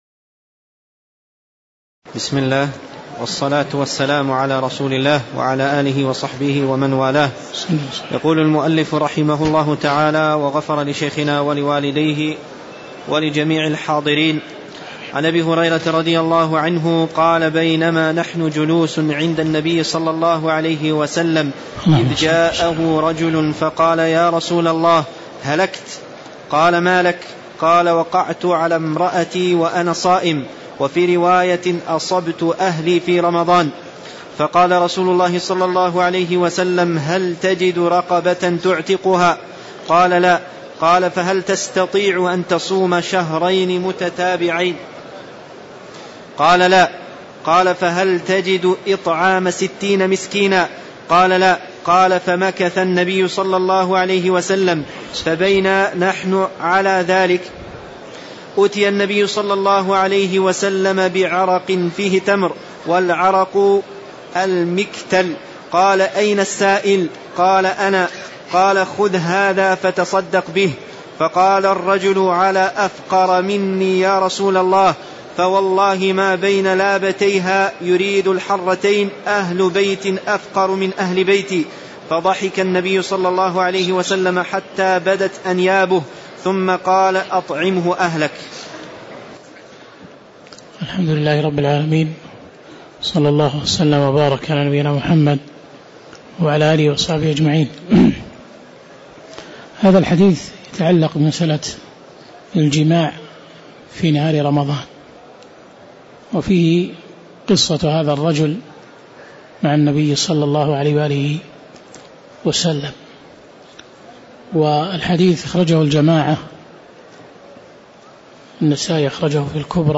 تاريخ النشر ١٣ رجب ١٤٣٧ هـ المكان: المسجد النبوي الشيخ